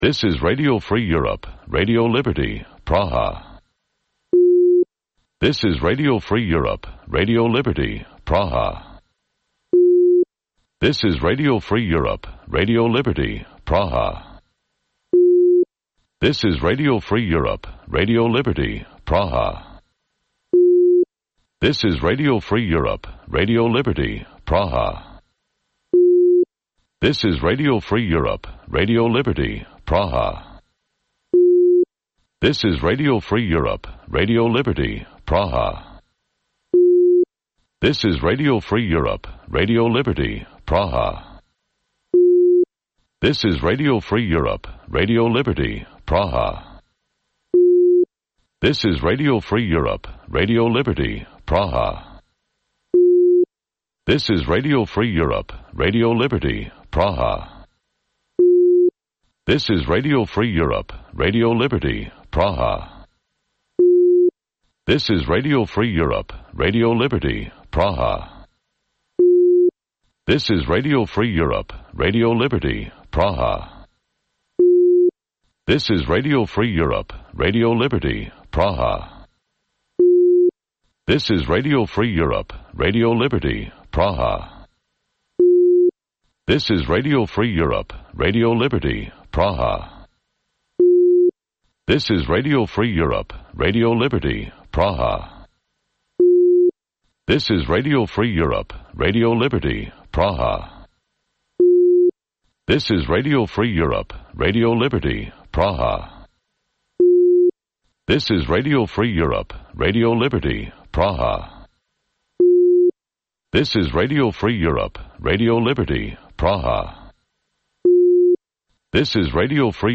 Вечірній ефір новин про події в Криму. Усе найважливіше, що сталося станом на цю годину.